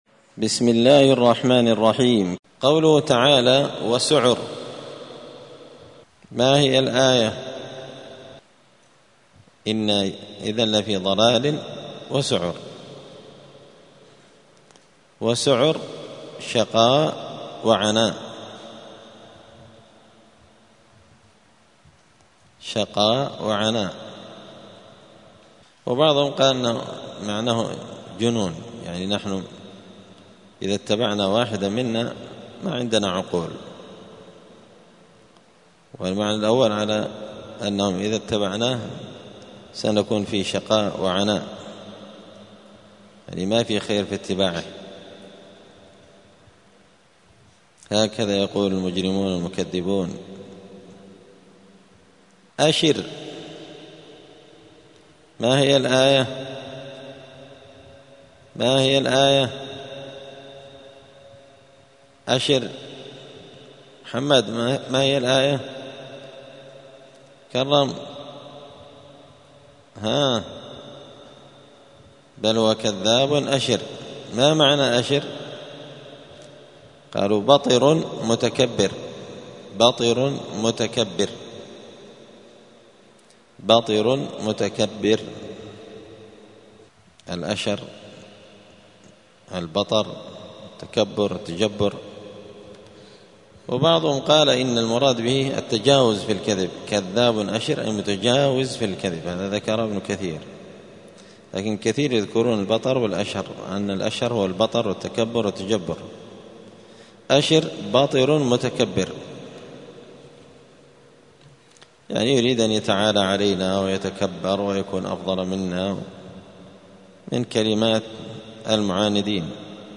زبدة الأقوال في غريب كلام المتعال الدرس الخامس والسبعون بعد المائة (175)